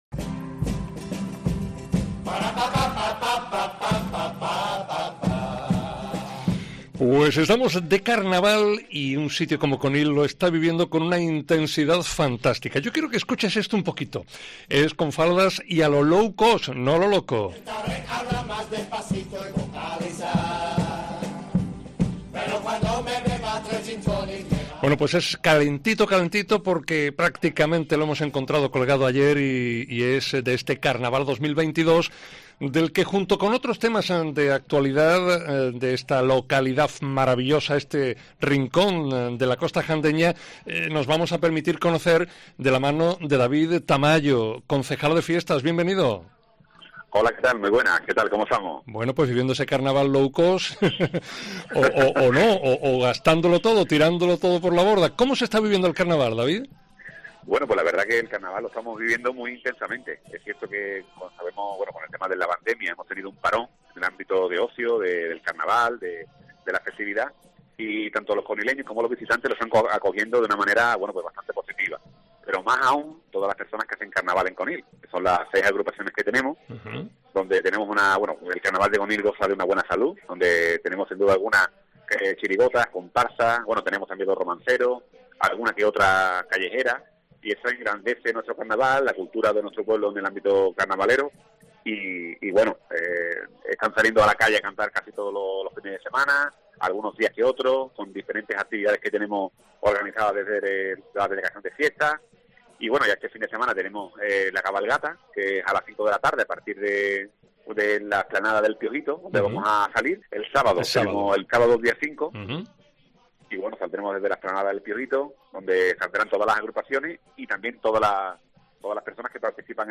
El objetivo de dicho mapa es ampliar la oferta de material turístico y el segundo de ellos nos lo cuenta en Mediodía COPE Provincia de Cádiz sin olvidar que se encuentran en los días grandes del Carnaval.